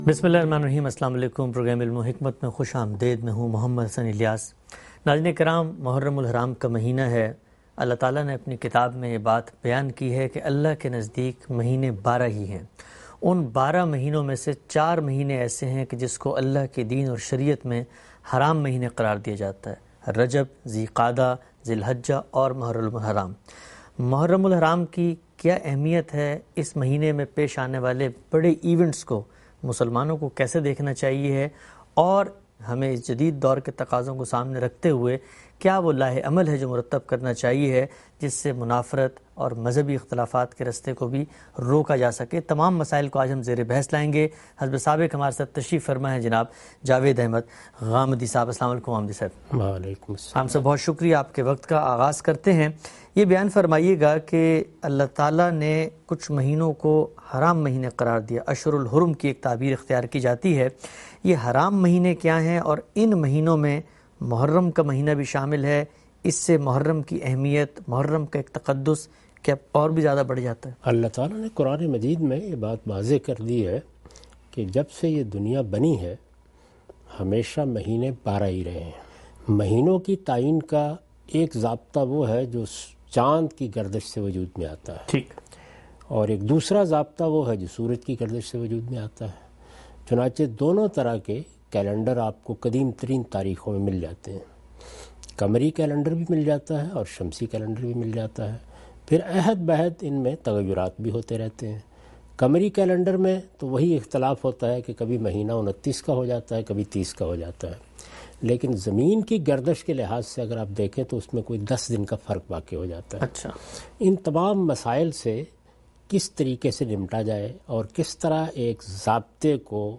In this program Javed Ahmad Ghamidi answers the questions about "Importance of Muharram".